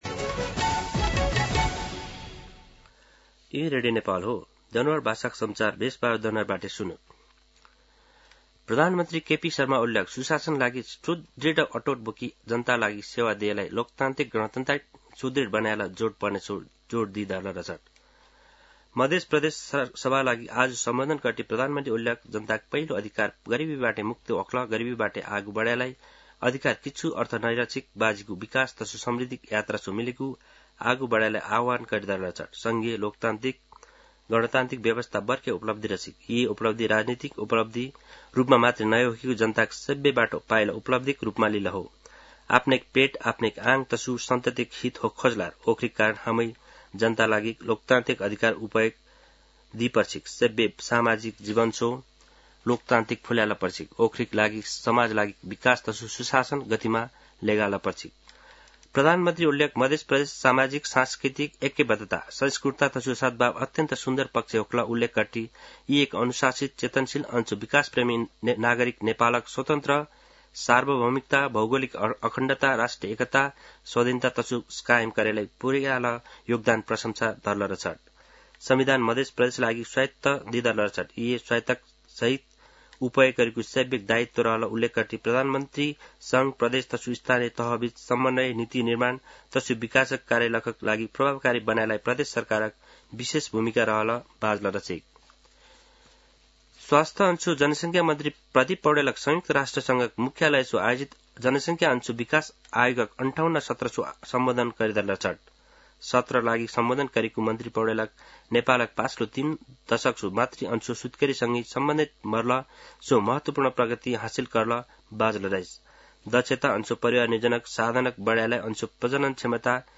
दनुवार भाषामा समाचार : २६ चैत , २०८१